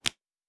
pgs/Assets/Audio/Fantasy Interface Sounds/Cards Place 02.wav
Cards Place 02.wav